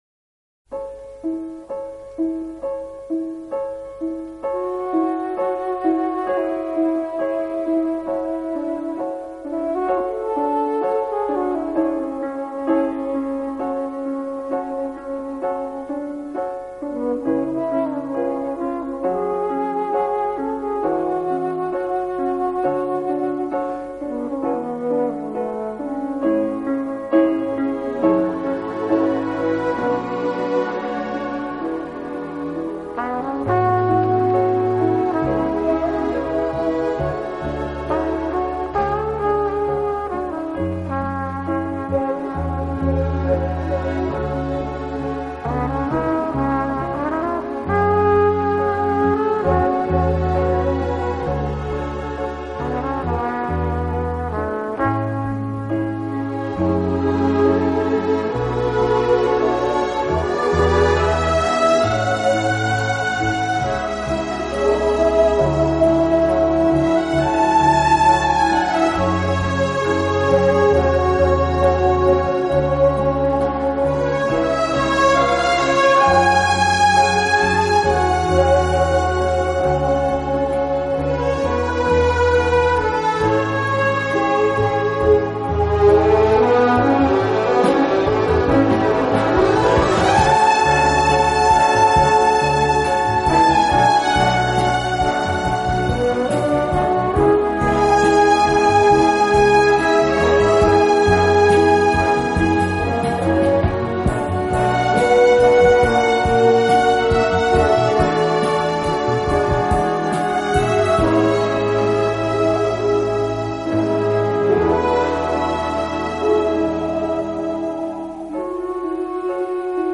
【轻音乐】
乐队以弦乐为中坚，演奏时音乐的处理细腻流畅，恰似一叶轻舟，随波荡